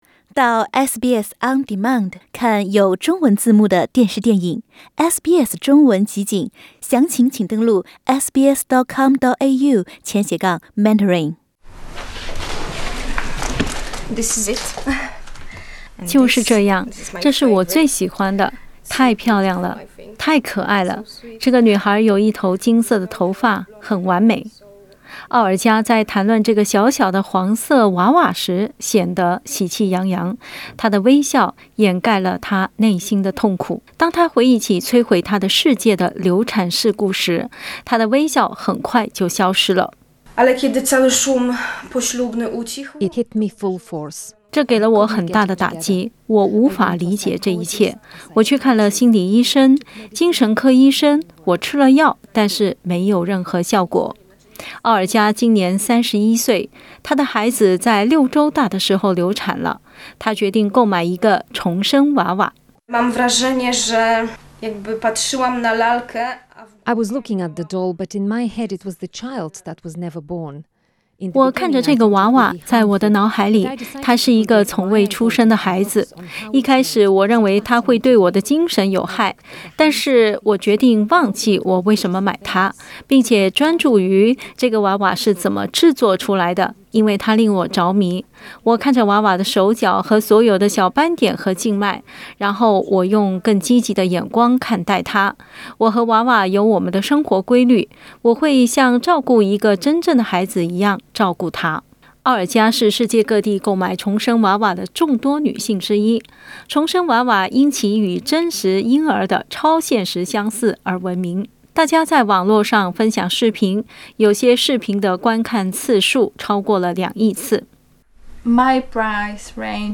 这个产品旨在帮助一些女性度过不孕不育和失去孩子的痛苦。 （点击图片收听报道）